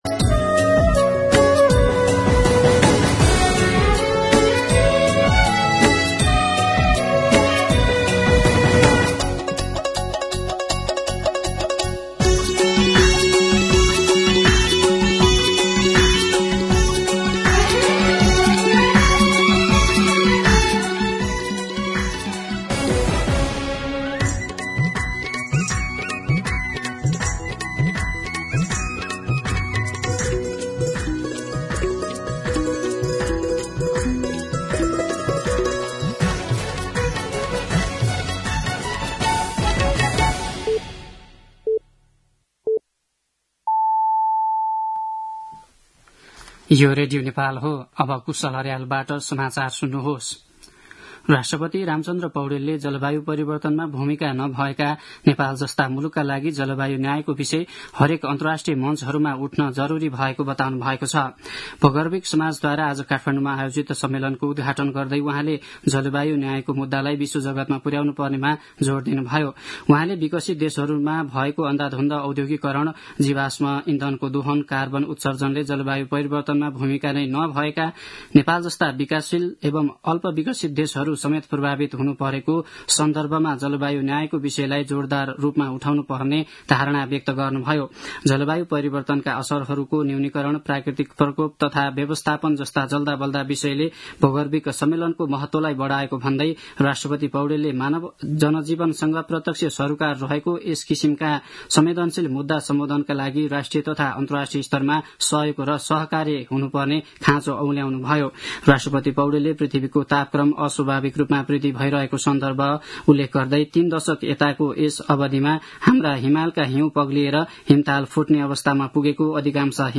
दिउँसो १ बजेको नेपाली समाचार : २७ फागुन , २०८१